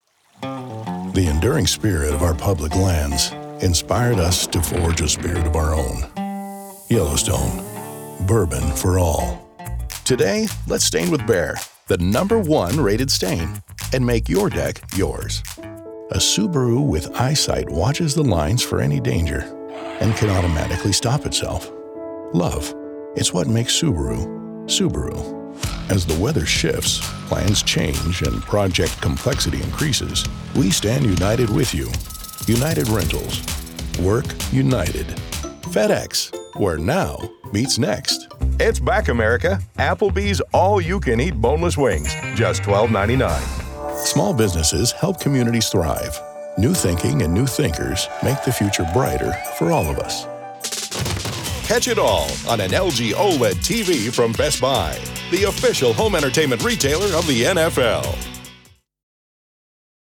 English (American)
Commercial, Distinctive, Versatile, Warm, Corporate
Commercial
He records from a professionally appointed home studio for clients across the globe.